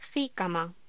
Locución: Jícama